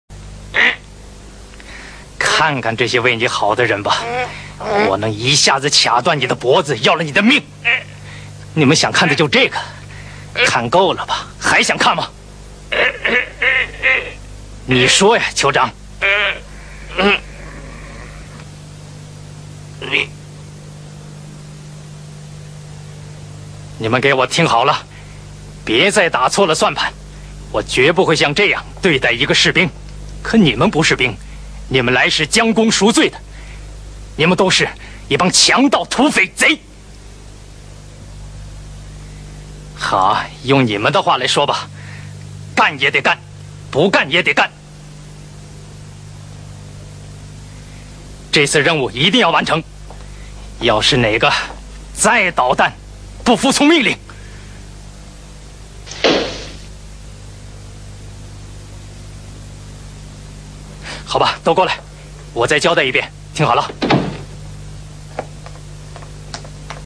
[23/9/2009]童自荣经典配音